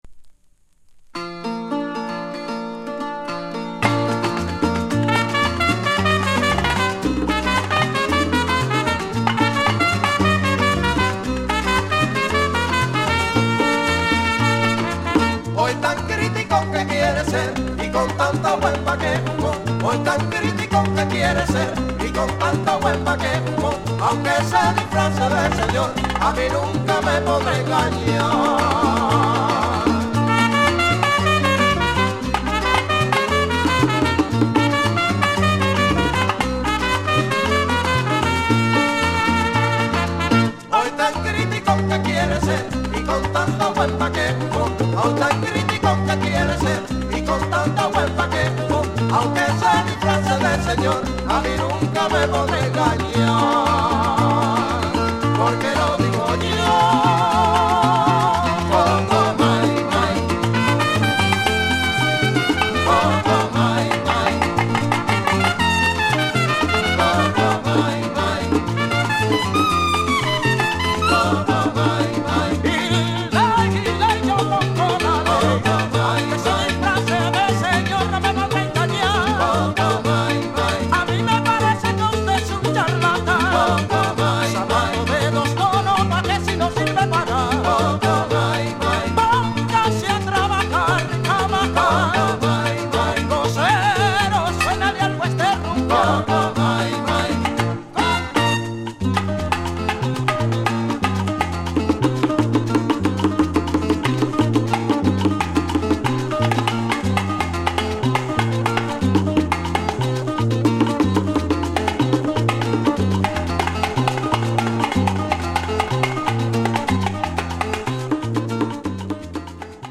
1976年に結成された、古のキューバン・ソンを後世に伝えるグループ